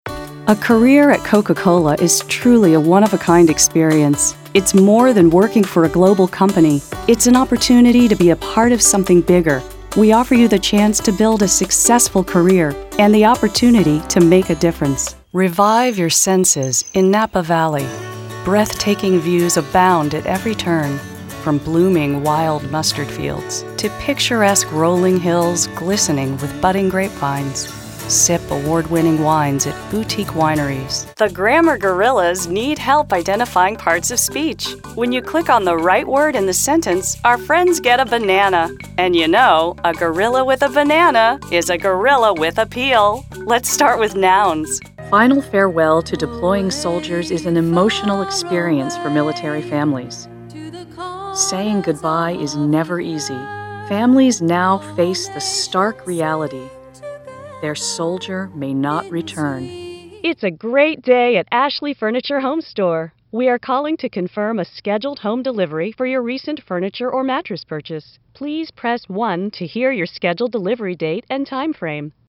Voiceover
Narration Demo